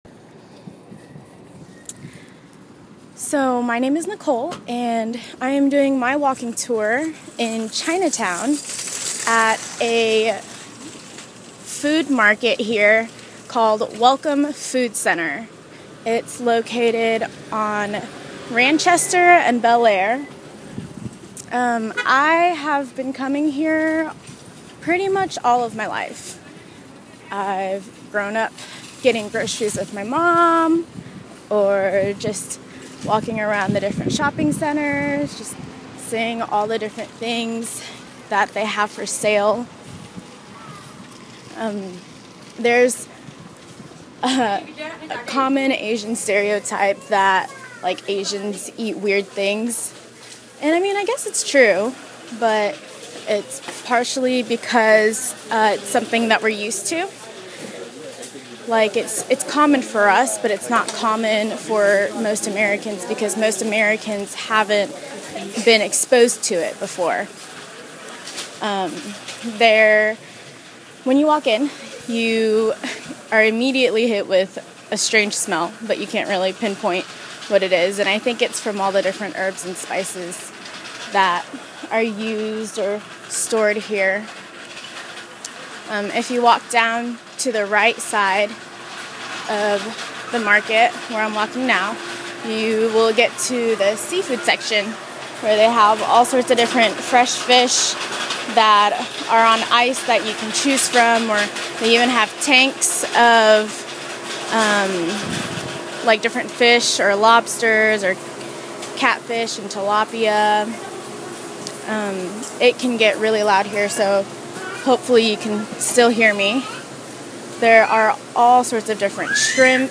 This tour is a brief walk-through of Welcome Food Market in Chinatown. I wanted to show that Asian stuff isn't weird, it's just different.